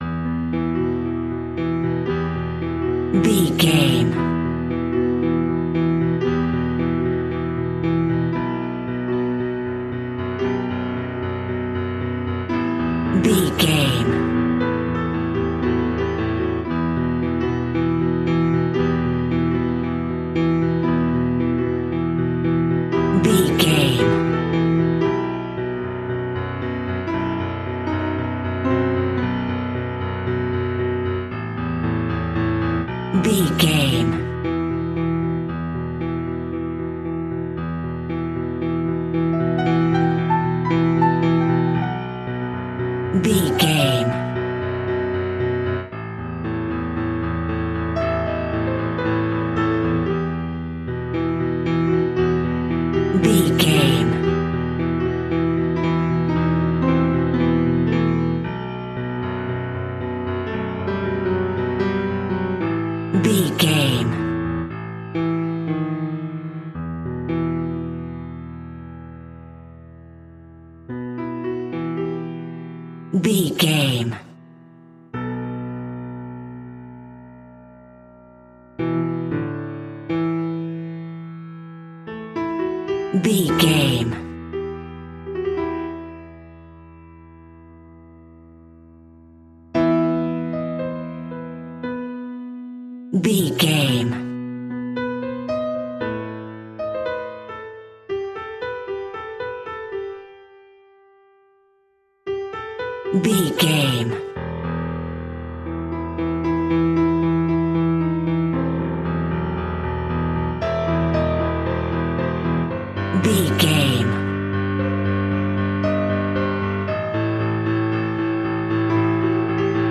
Classical Halloween Piano.
Aeolian/Minor
Slow
ominous
suspense
haunting
eerie